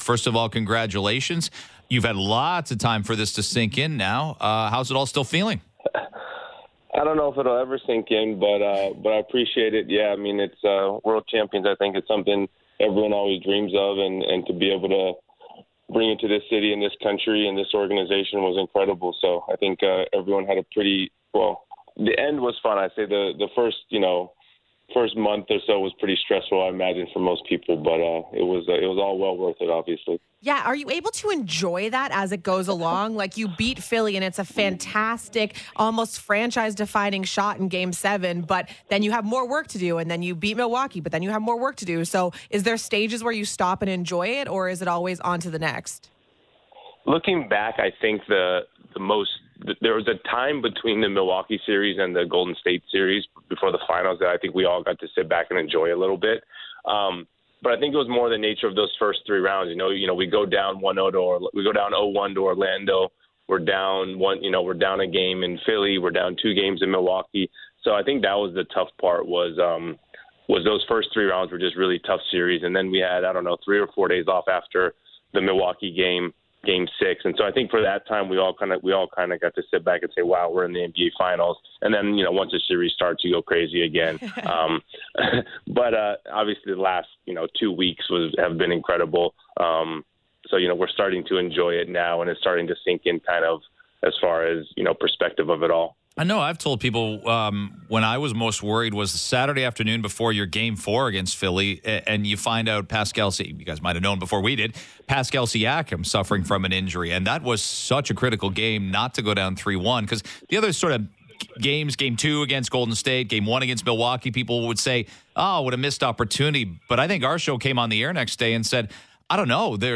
You can listen to Webster’s entire radio appearance using the radio player found above in this post where he talks, among other topics, about Marc Gasol’s impact after his trade-deadline acquisition and the possibility of bringing back Vince Carter for his final season.